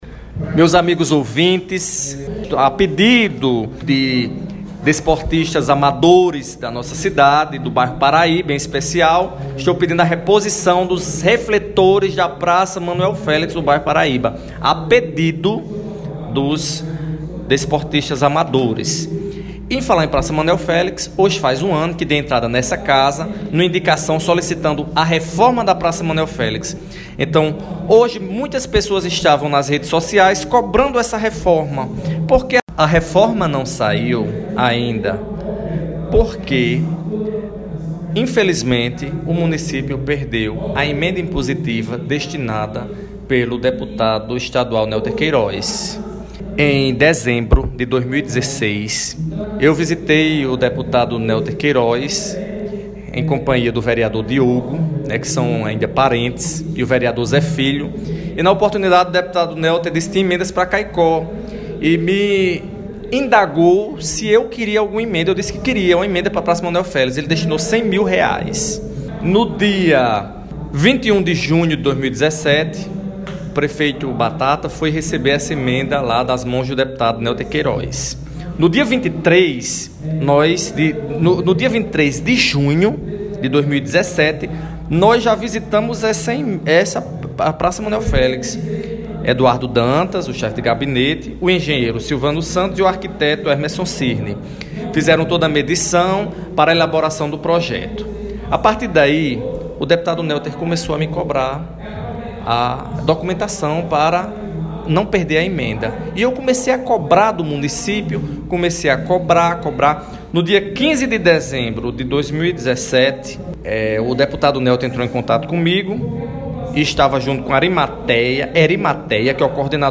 Confira fala do vereador: